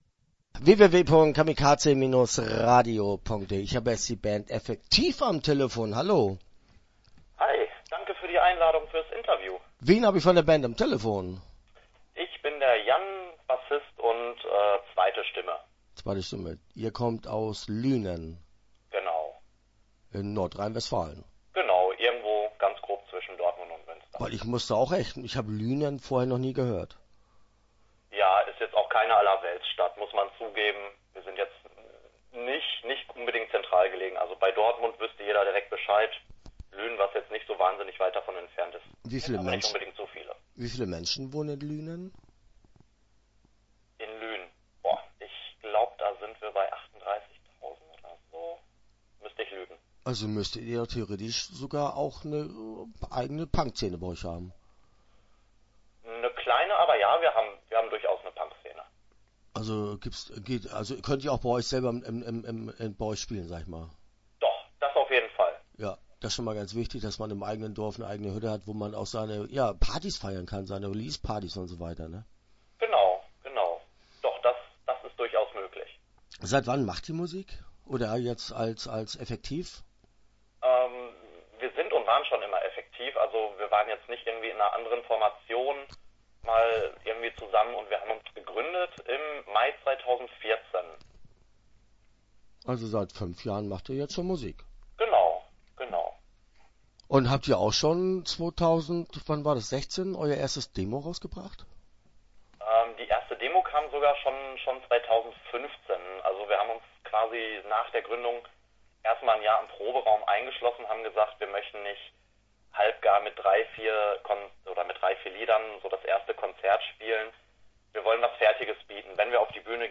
EFFEKTIEF - Interview Teil 1 (10:32)